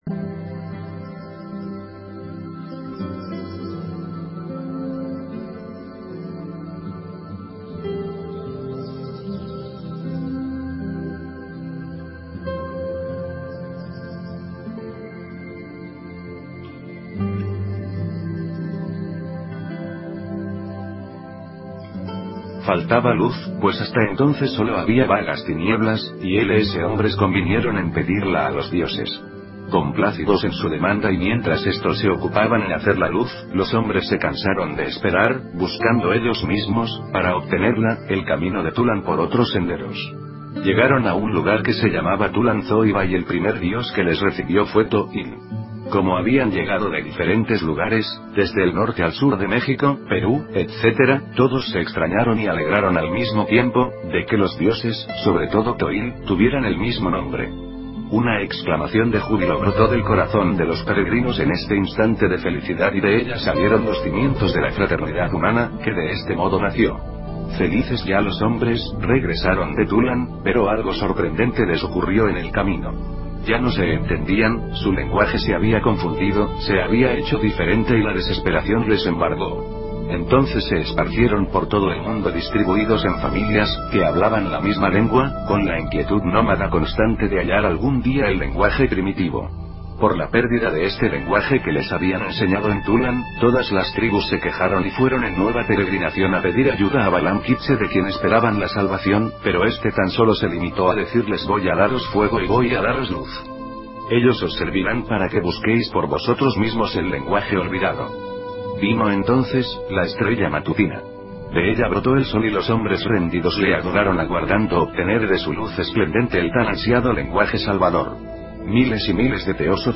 Audio Libro